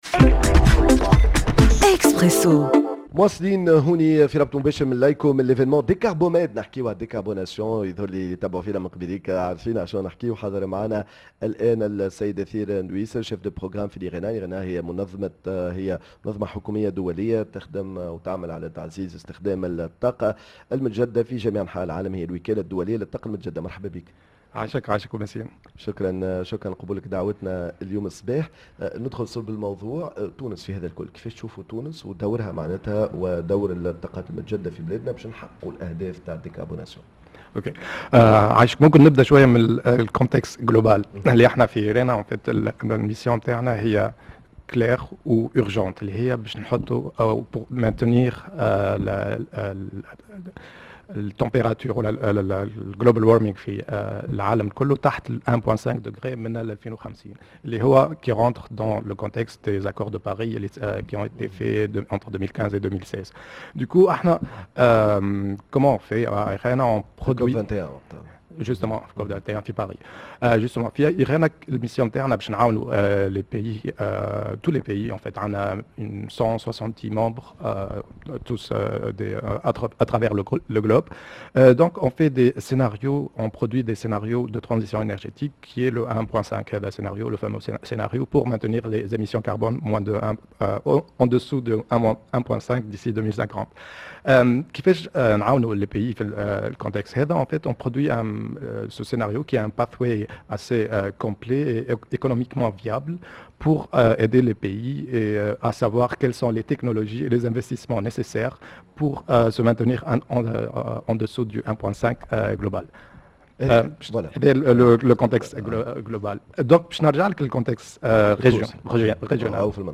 dans un plateau spécial